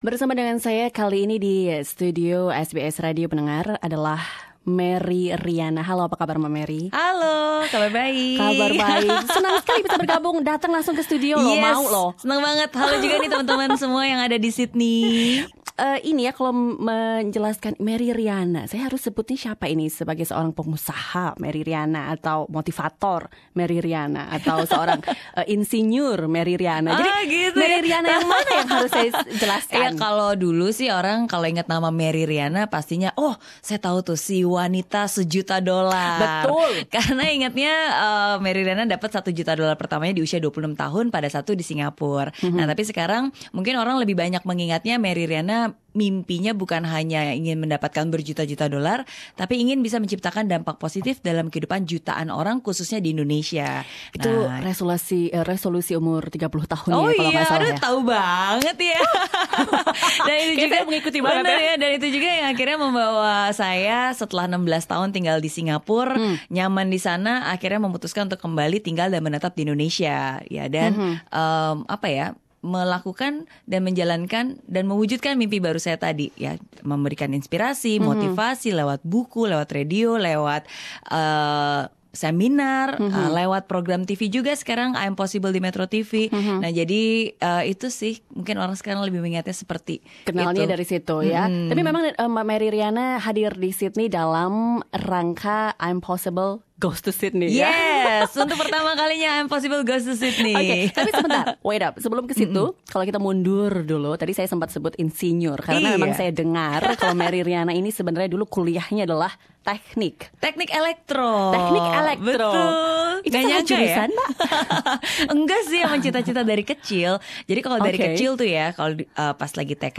SBS Radio berbincang dengan Merry Riana, motivator wanita nomor 1 di Indonesia yang juga menjadi penulis bestseller dan pengusaha. Merry Riana berbagi kisah tentang perjuangan dan perjalanannya menjadi jutawan dolar termuda, serta apa yang dilakukan setelahnya.
Merry Riana at SBS studio Source: RO